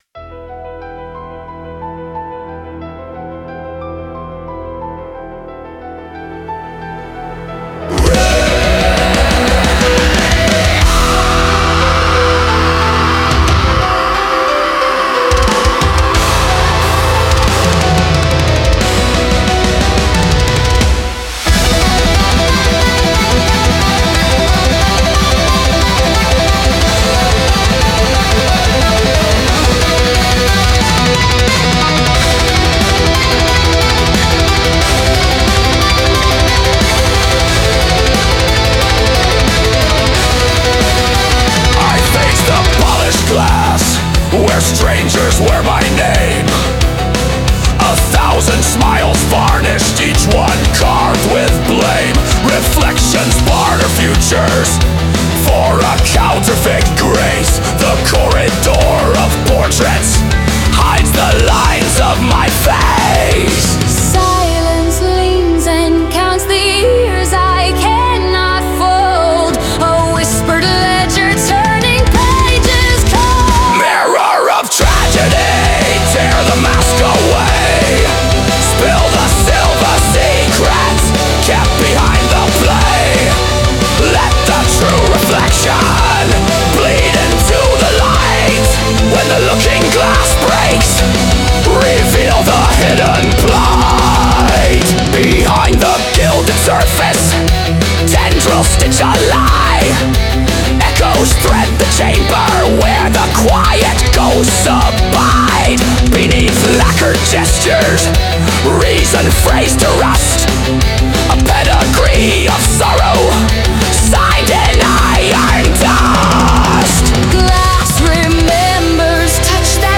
Melodic Death Metal